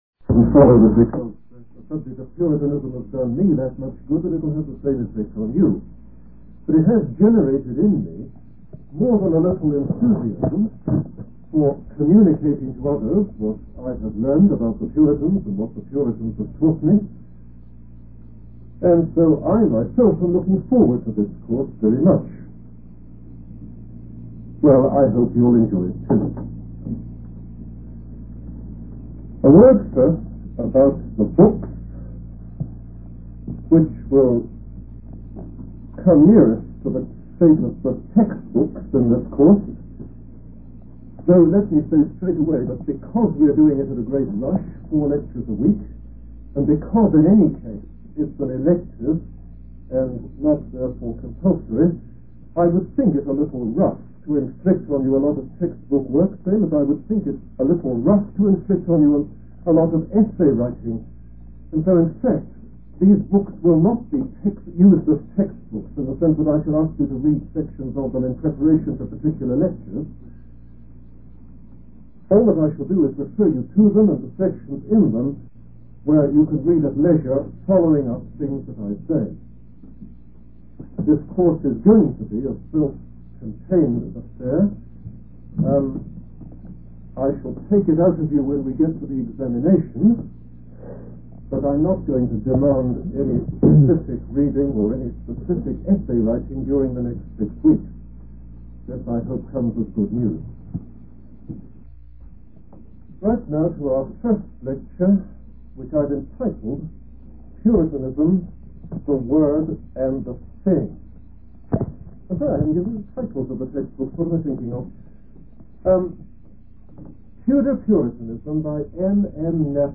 In this sermon, the speaker begins by emphasizing the importance of understanding the overall standpoint before delving into the subject. He states that this course is a study of history and highlights the need to look for the essence of history and what to focus on when studying it.